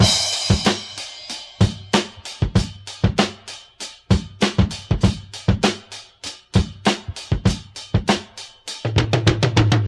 132 Bpm Drum Groove G# Key.wav
Free drum beat - kick tuned to the G# note. Loudest frequency: 1478Hz
132-bpm-drum-groove-g-sharp-key-3qW.ogg